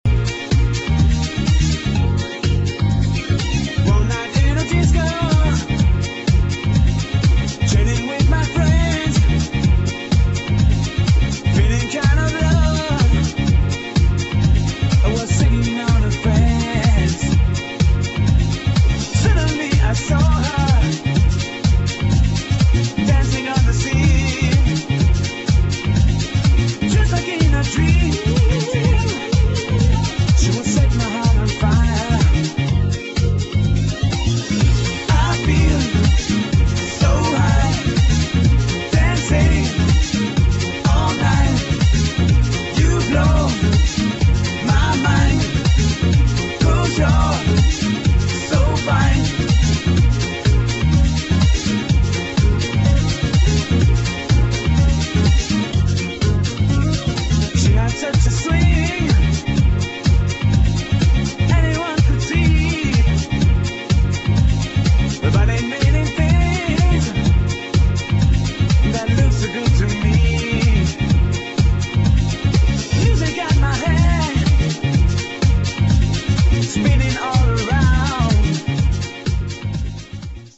[ FRENCH HOUSE ]